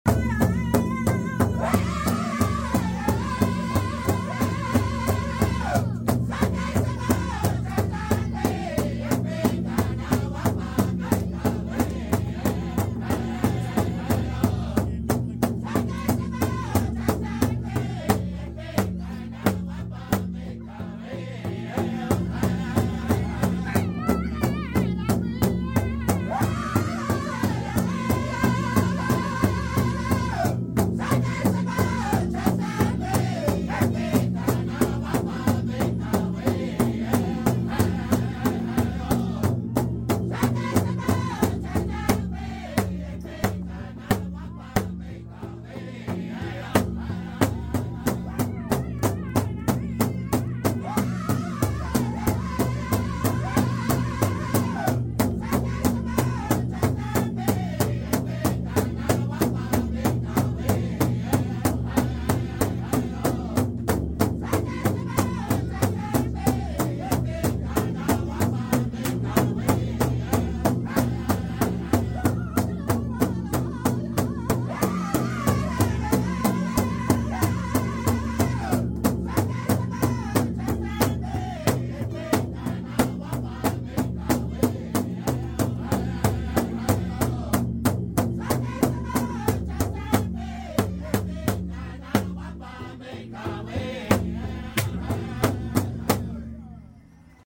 powwow in ottawa